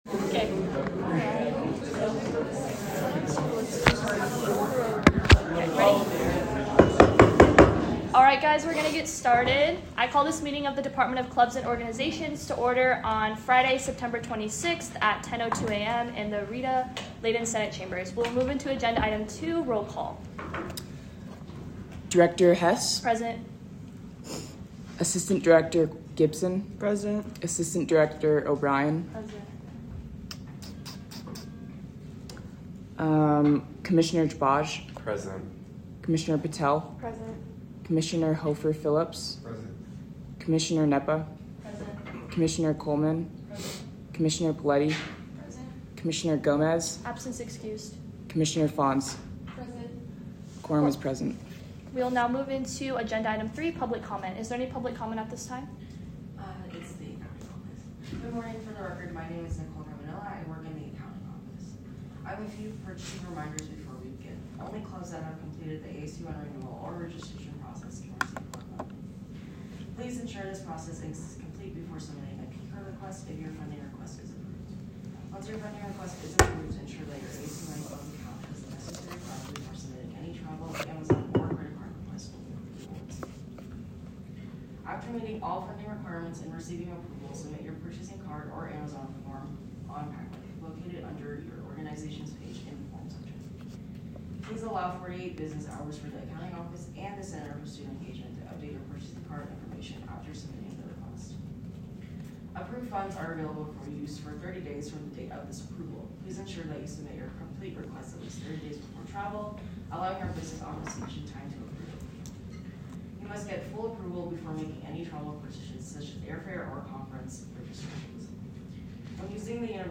Location : Rita Laden Senate Chambers - located on the third floor of the JCSU
Audio Minutes